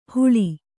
♪ huḷi